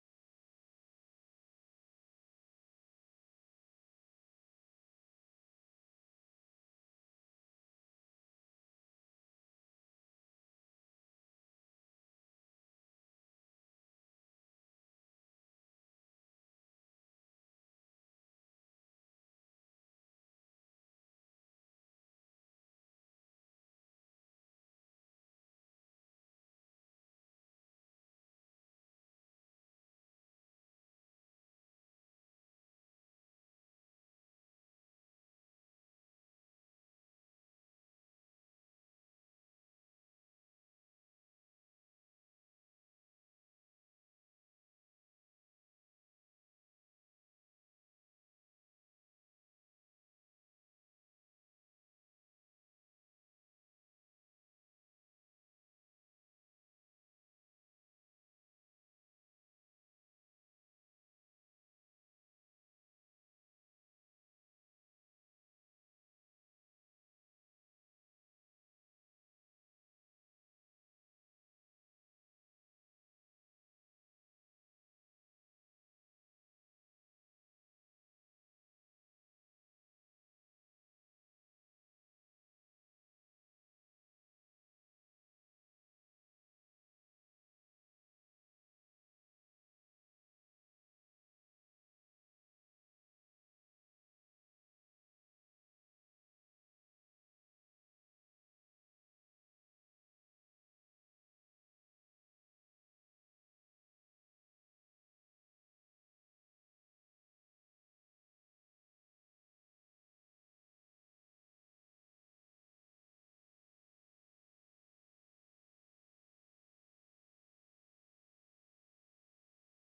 تاريخ النشر ٢٦ رمضان ١٤٣٩ هـ المكان: المسجد الحرام الشيخ